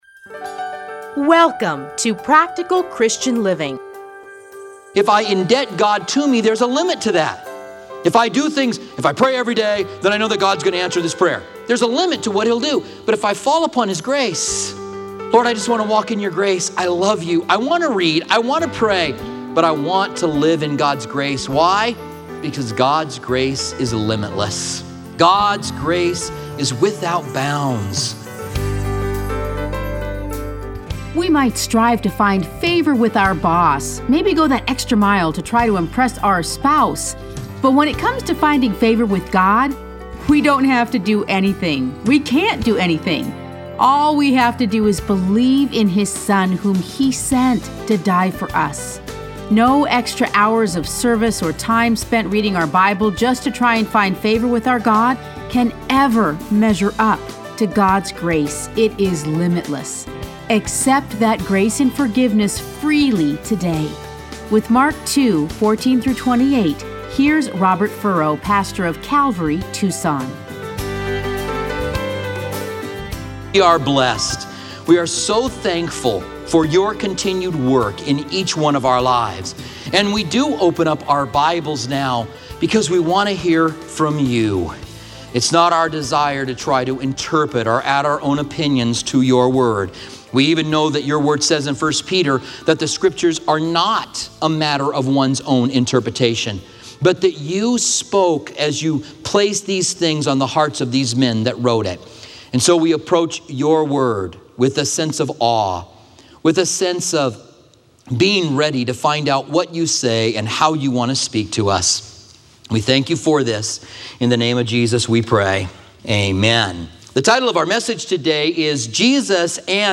Listen to a teaching from Mark 2:14-28.